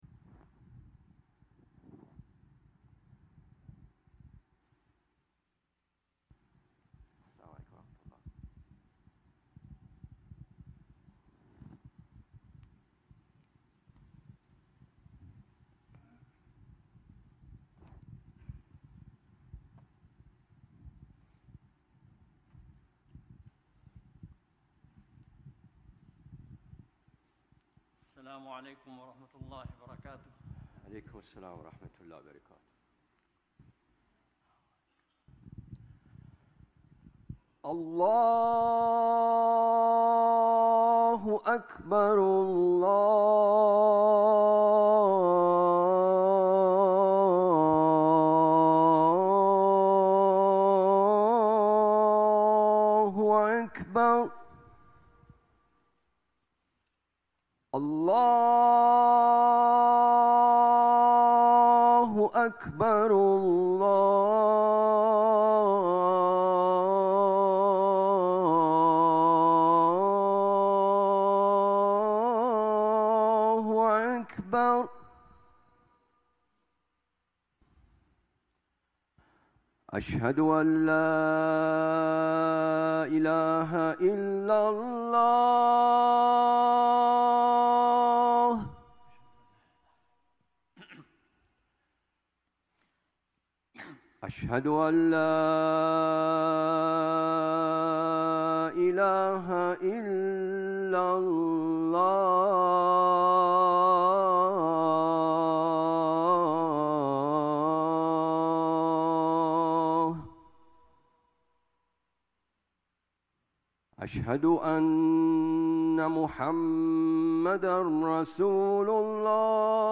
Friday Khutbah -"In the Pursuit of Good"